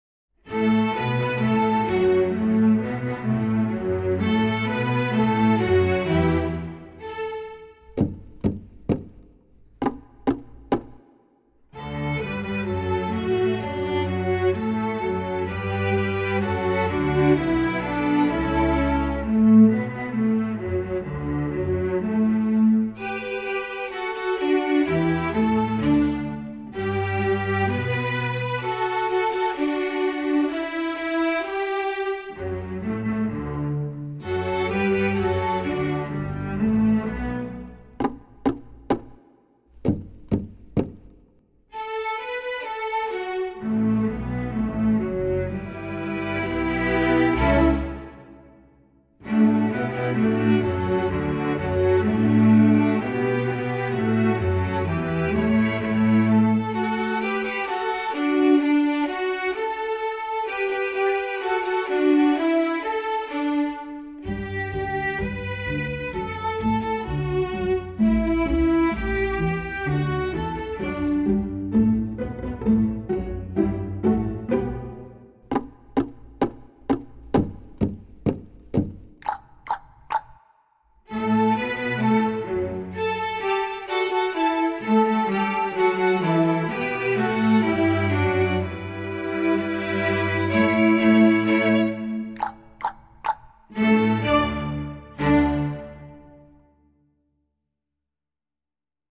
Voicing: String Orchestsra